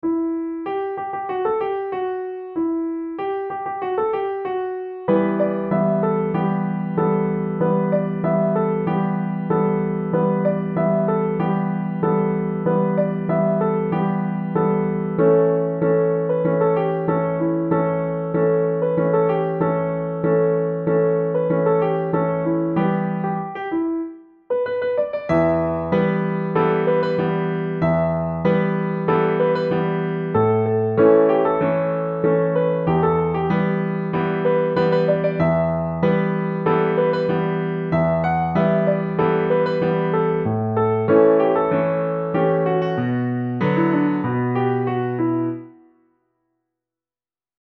E minor
Romantic